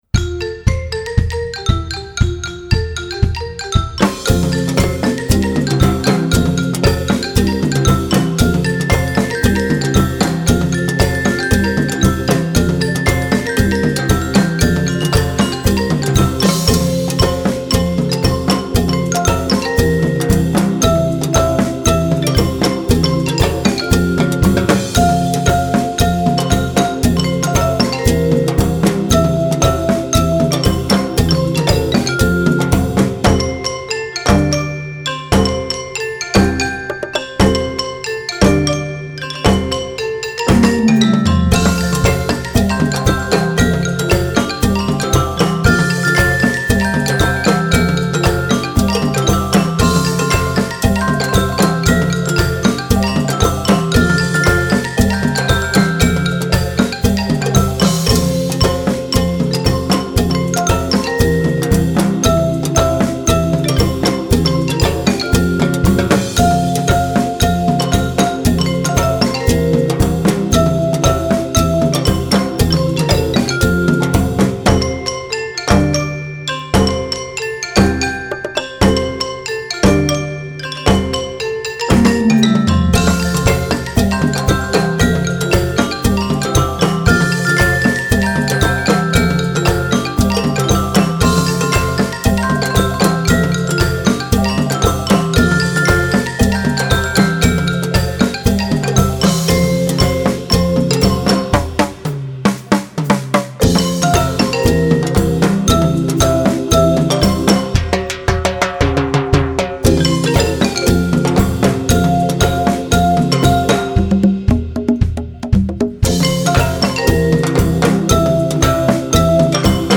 Besetzung: Instrumentalnoten für Schlagzeug/Percussion